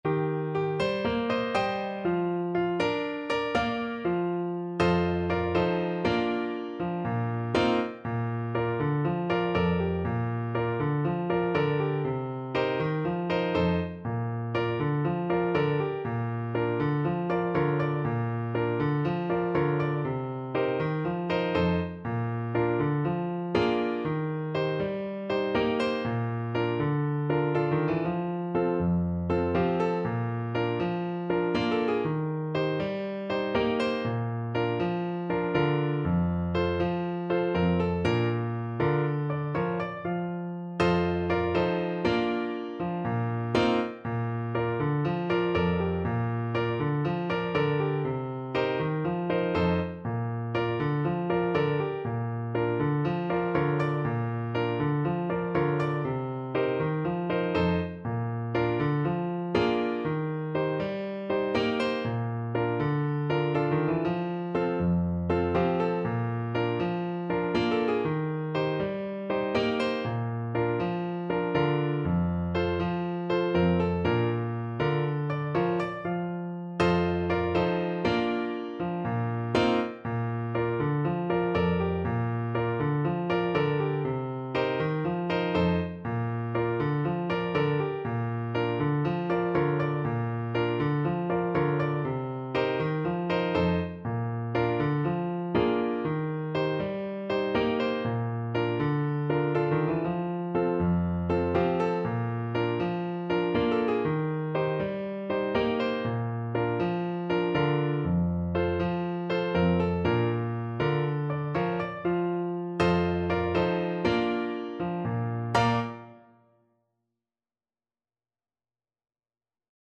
Calypso = 120